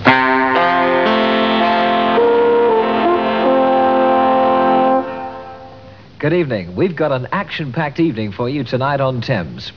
From the early 70s, here is the familiar Thames jingle followed by in-vision continuity announcer, David Hamilton, saying, "Good evening. We've got an action packed evening for you tonight on Thames."
(This isn't actually a genuine Thames continuity announcement, but rather part of a spoof used at the beginning of an episode of Monty Python's Flying Circus.